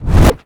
casting_charge_whoosh_buildup6.wav